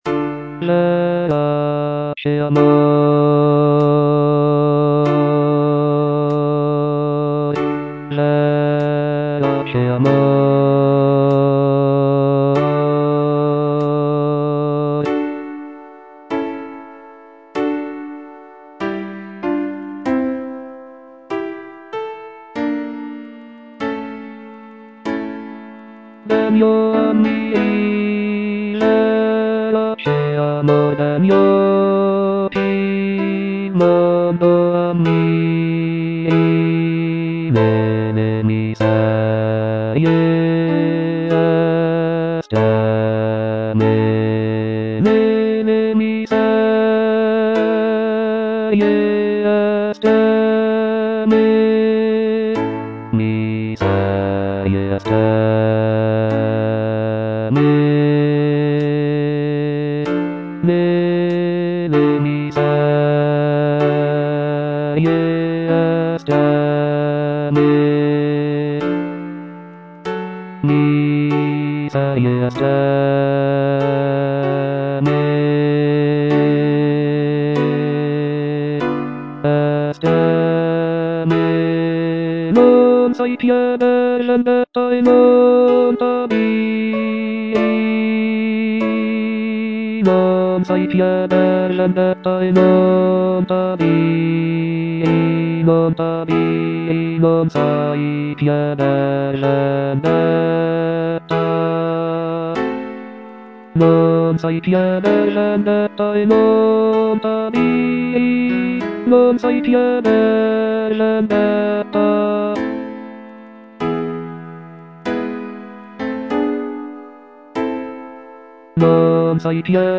C. MONTEVERDI - LAMENTO D'ARIANNA (VERSION 2) Coro IV: Verace amor - Bass (Voice with metronome) Ads stop: auto-stop
The music is characterized by its expressive melodies and poignant harmonies, making it a powerful and moving example of early Baroque vocal music.